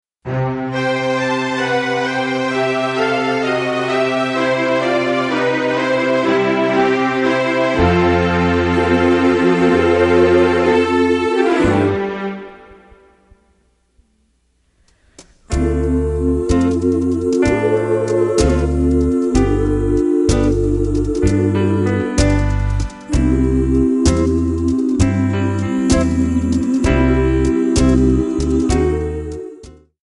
MPEG 1 Layer 3 (Stereo)
Backing track Karaoke
Pop, Oldies, 1950s